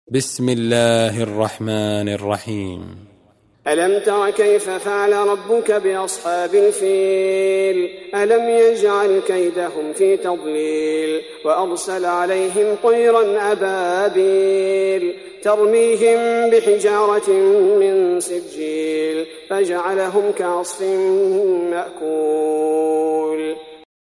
সূরা আল-ফীল ডাউনলোড mp3 Abdul bari al thubaity উপন্যাস Hafs থেকে Asim, ডাউনলোড করুন এবং কুরআন শুনুন mp3 সম্পূর্ণ সরাসরি লিঙ্ক